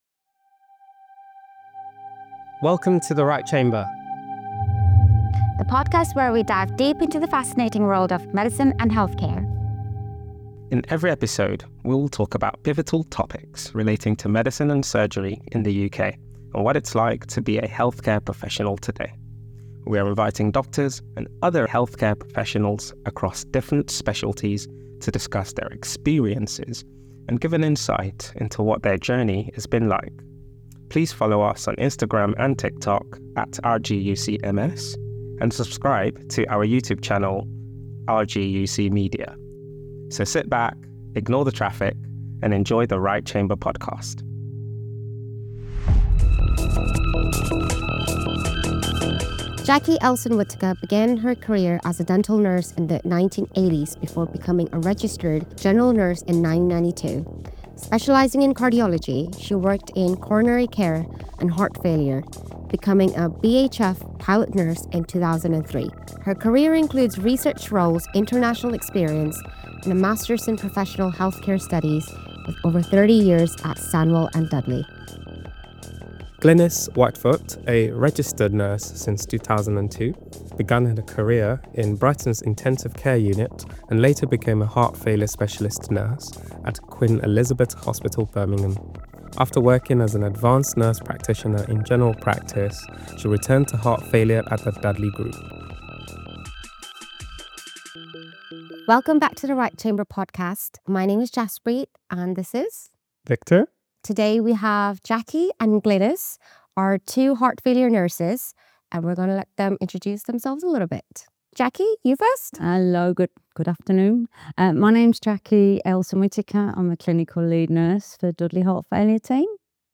The Right Chamber – E12 Heart Matters: Conversations with Heart Failure Nurses